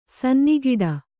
♪ sanni giḍa